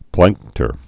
(plăngktər)